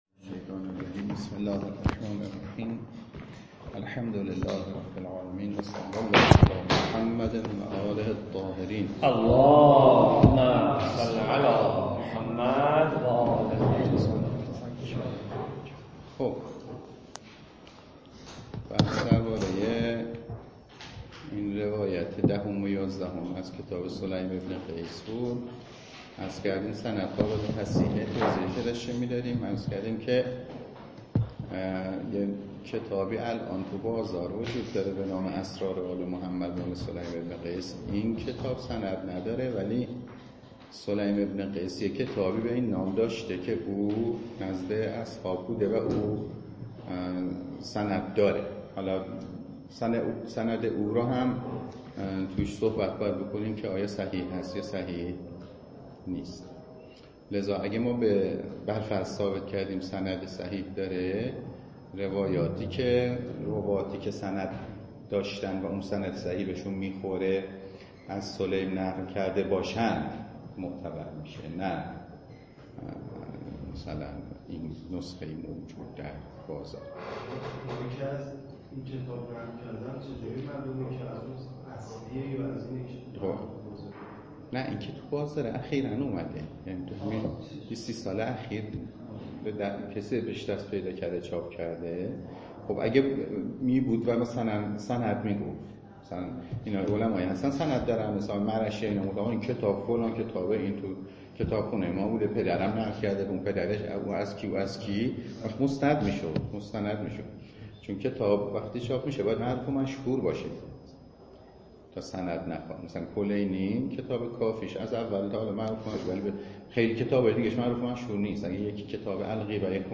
درس خارج فقه ولایت فقیه